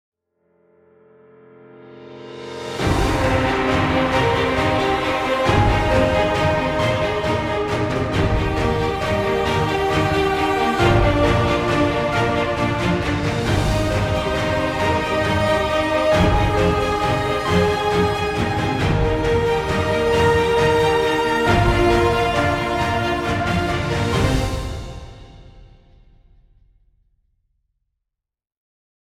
Trailer music, exciting intro, or battle scenes.